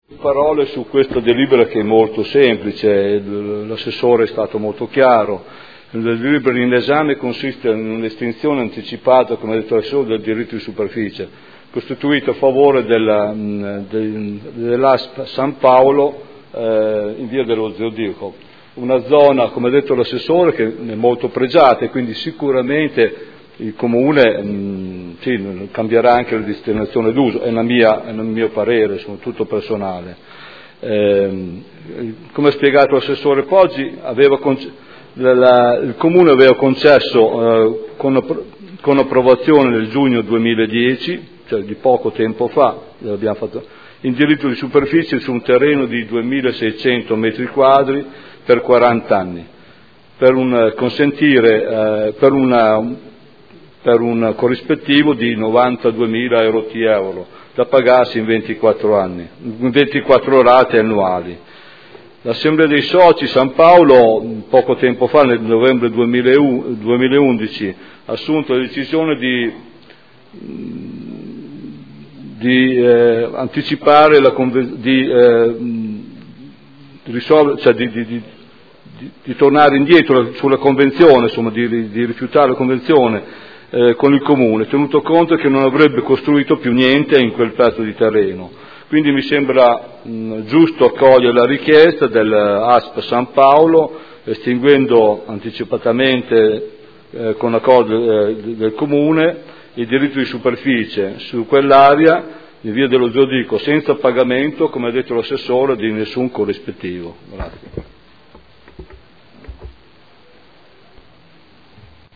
Stefano Goldoni — Sito Audio Consiglio Comunale
Proposta di deliberazione: Estinzione anticipata del diritto di superficie costituito a favore di ASP San Paolo sugli immobili in Via dello Zodiaco. Dibattito